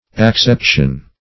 Acception \Ac*cep"tion\, n. [L. acceptio a receiving, accepting: